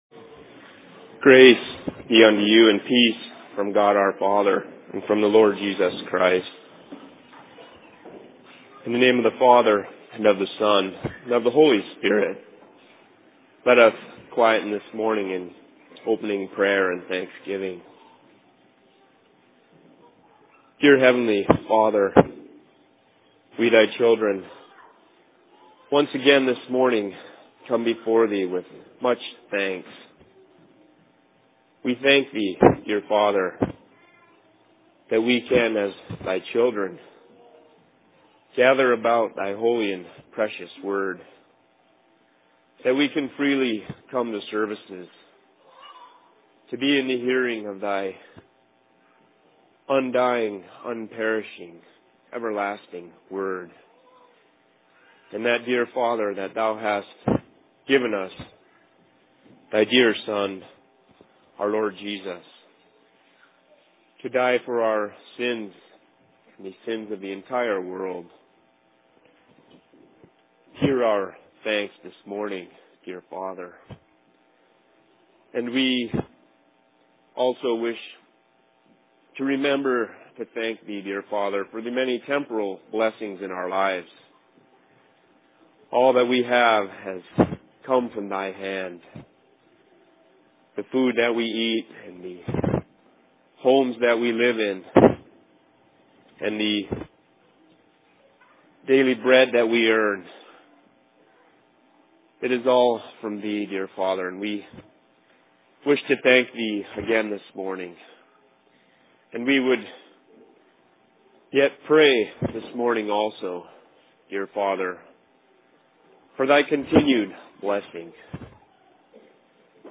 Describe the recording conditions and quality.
Location: LLC Seattle